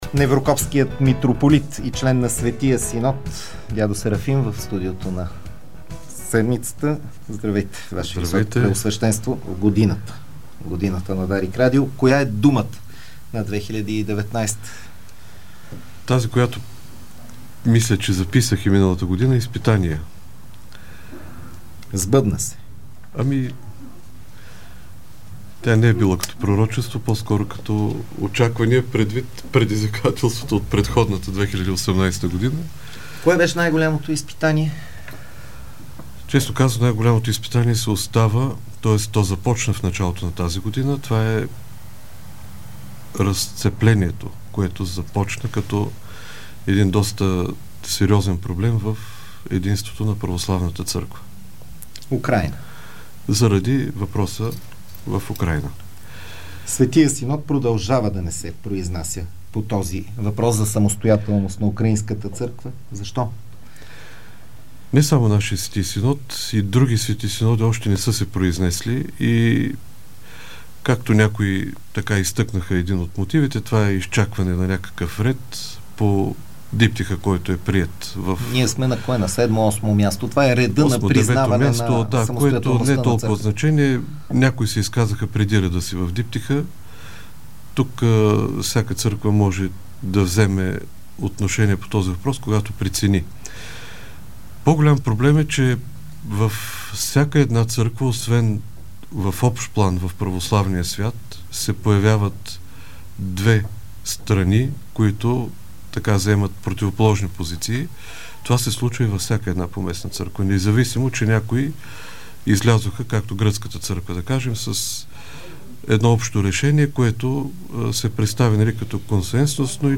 Това каза Неврокопският митрополит Серафим в предаването „Годината” по Дарик радио.